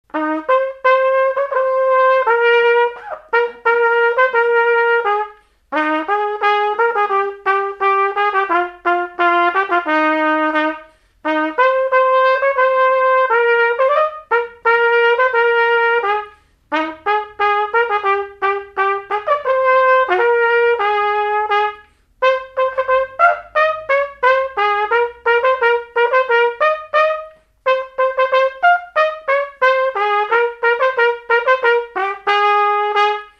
Marche de mariée
marche de cortège de noce
Pièce musicale inédite